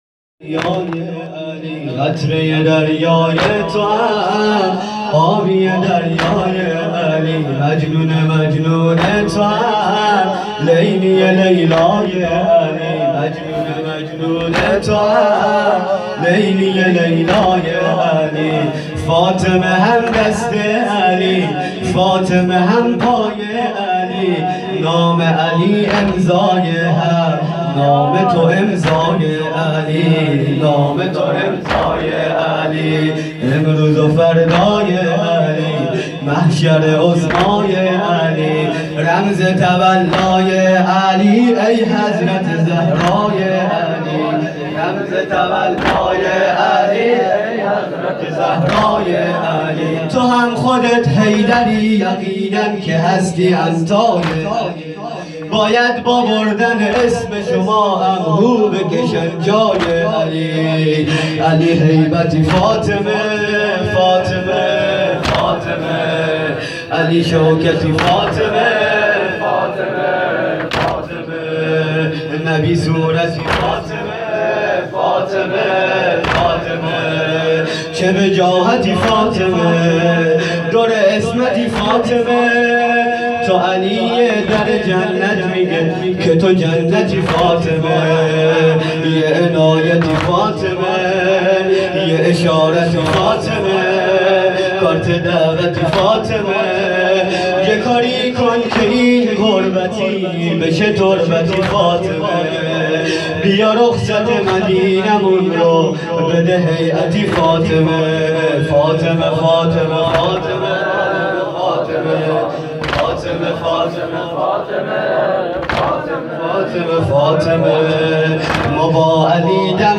توضیحات: هیئت صادقیون حوزه علمیه زابل